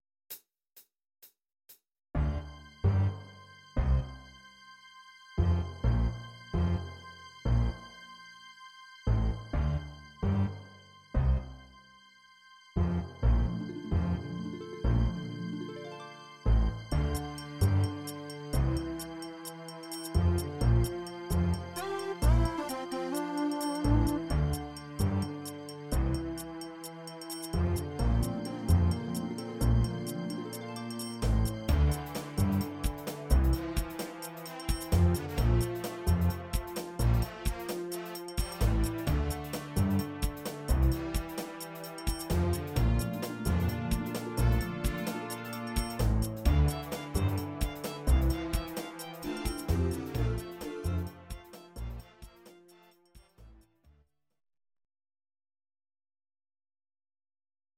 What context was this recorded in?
Audio Recordings based on Midi-files